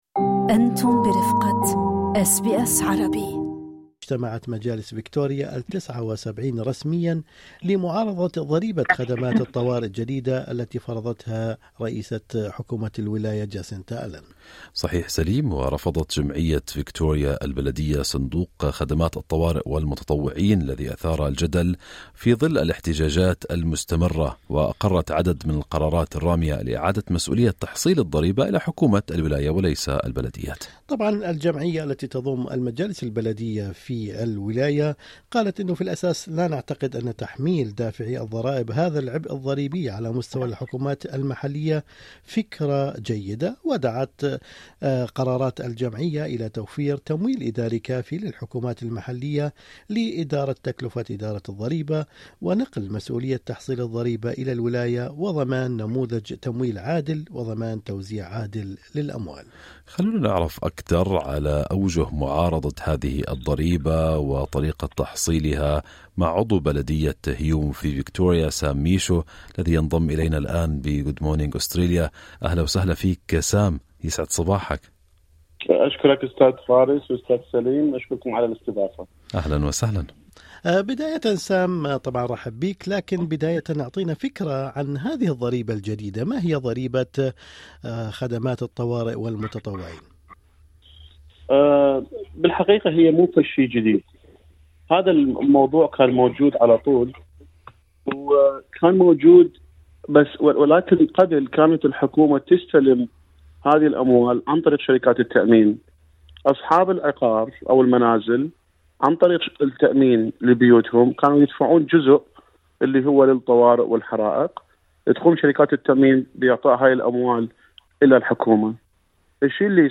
وقال عضو مجلس هيوم سام ميشو في لقاء إذاعي مع أس بي أس عربي إن هذه الضريبة ستُضاف على رسوم البلدية (Rates) بحسب قرار الولاية وهذا يعني أن مَن يقوم بجبايتها المجلس البلدي.